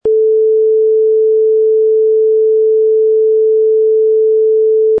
INDACO 438Hz
indaco438demo.mp3